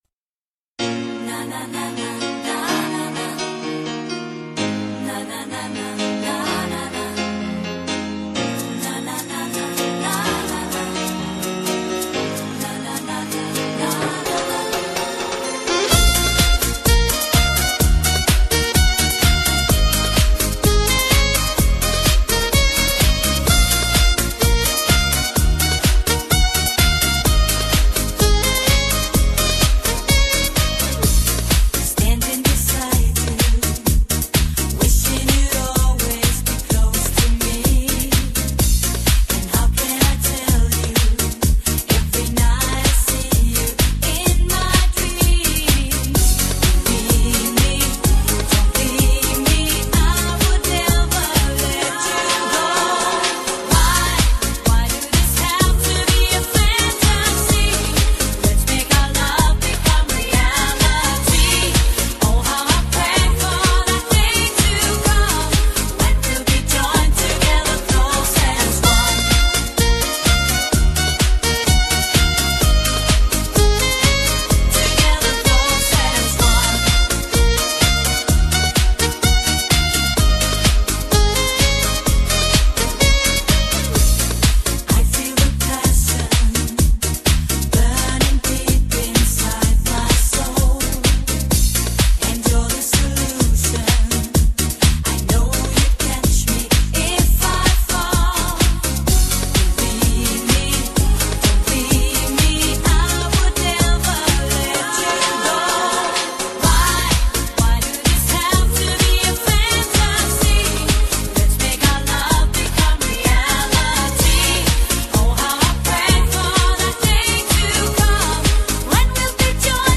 世界R&BDJ舞曲 激情巅峰跨越全球 迪厅王者至尊
炽热的音乐疯狂的节奏充满激情的活力
非凡的音质，汽车必备旅行设势不可挡的至尊DJ。